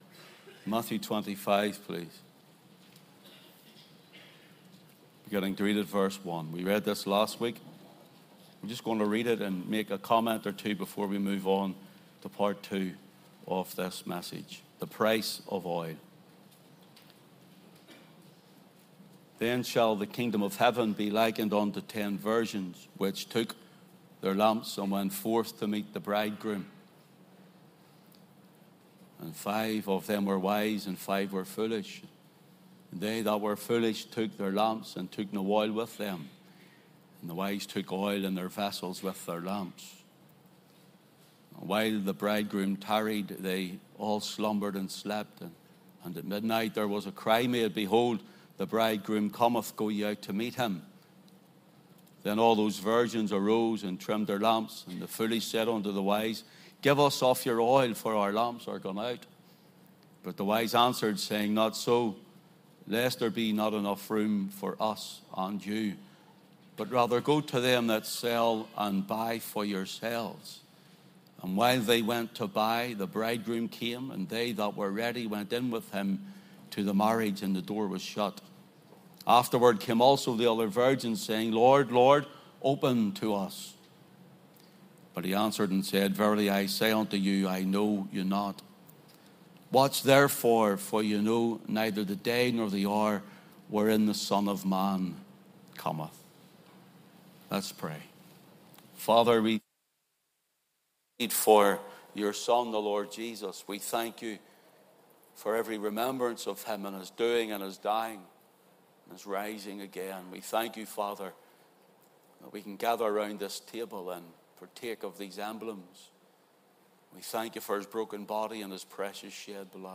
Sermons and Bible Teachings